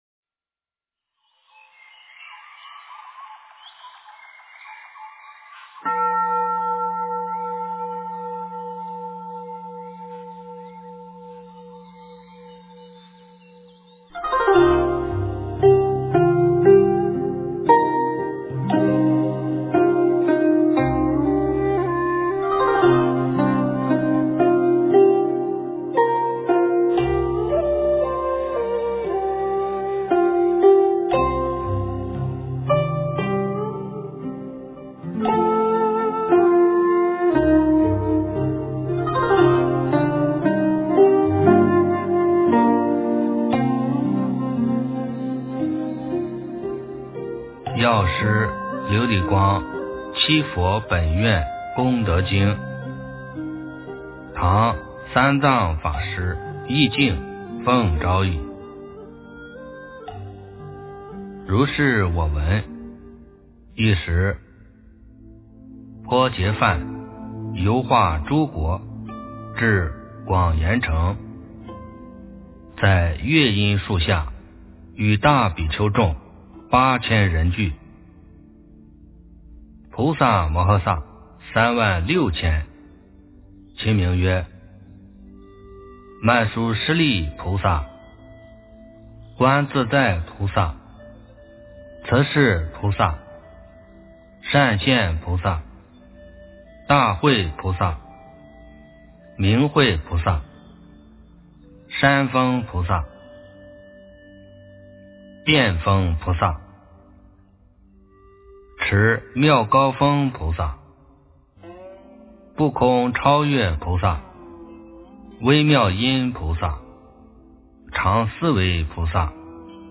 药师琉璃光七佛本愿功德经 - 诵经 - 云佛论坛